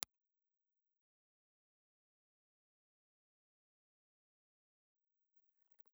Impulse Response file of the Toshiba B ribbon microphone with HPF position 2
Toshiba_B_HPF2_IR.wav